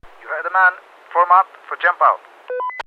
Tag: 战争 语音 战斗喷气机 演讲 军事 飞行员 无线电 飞机 飞机 样品 要求 战斗 男性